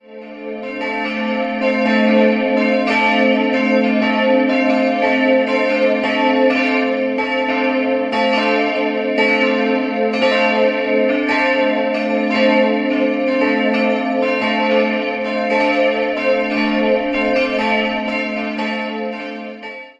Das Obergeschoss des Turms wurde jedoch erst 1795 errichtet. 3-stimmiges TeDeum-Geläute: a'-c''-d'' Die beiden größeren Glocken wurden 1972 in der Glockengießerei Heidelberg gegossen, die kleine bereits 1908 bei Oberascher in München.